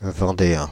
Ääntäminen
Ääntäminen Paris: IPA: [vɑ̃.deɛ̃] Haettu sana löytyi näillä lähdekielillä: ranska Käännös Substantiivit 1.